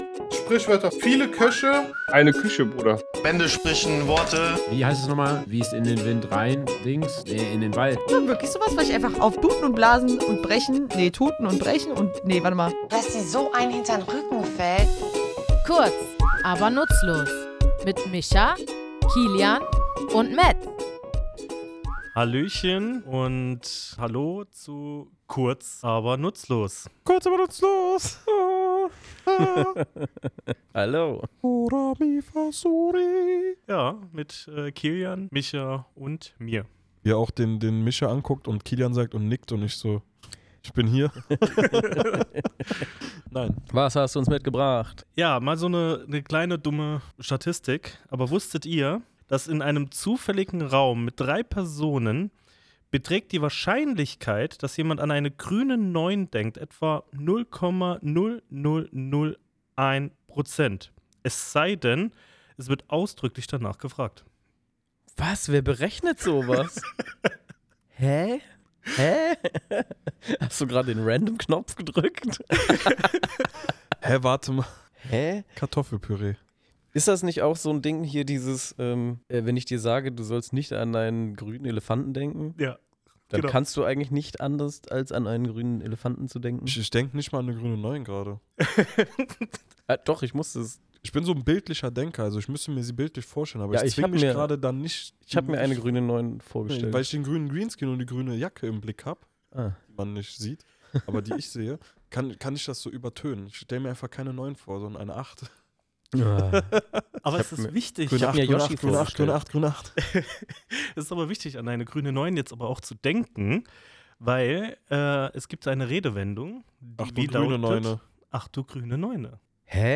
Woher stammt dieser Ausruf des Erstaunens, und was hat es mit der „grünen Neune“ auf sich? Wir, drei tätowierende Sprachliebhaber, gehen in unserem Tattoostudio den Ursprüngen und Bedeutungen dieses kuriosen Ausdrucks nach.